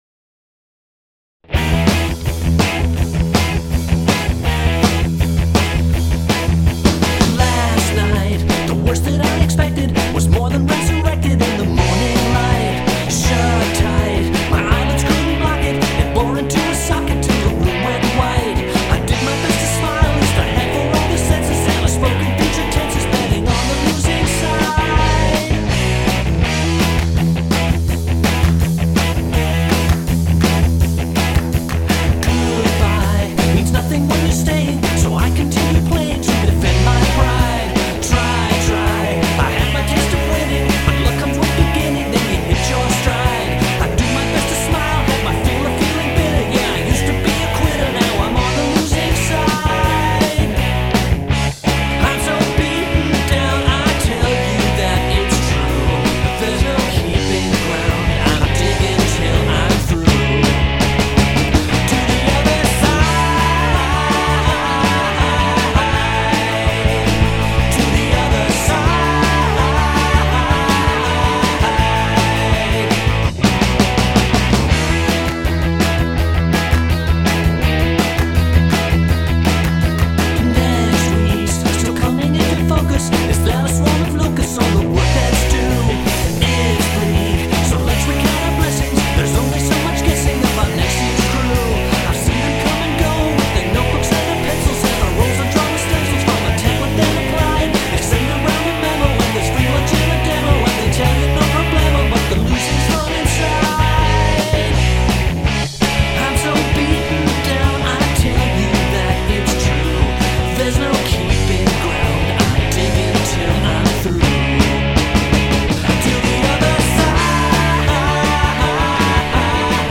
I appreciate the vocal dexterity required to sing those lyrics.
It works really well here!